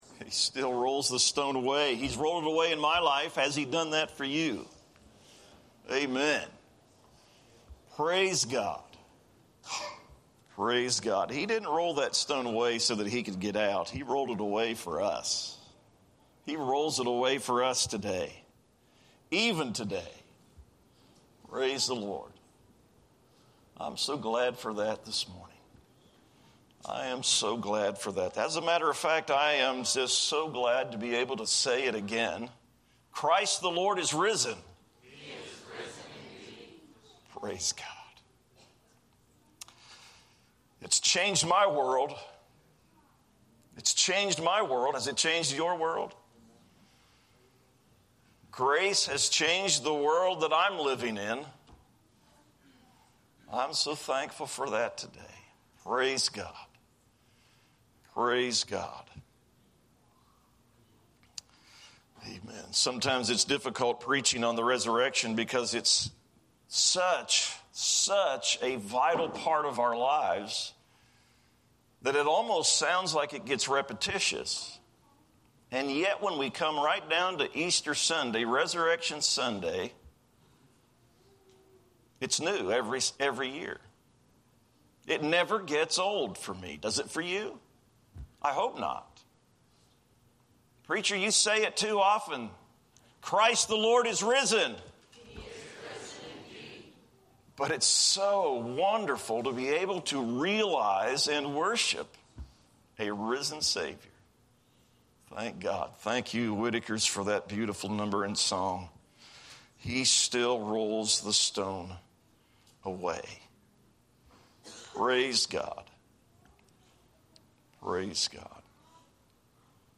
An Easter Sunday sermon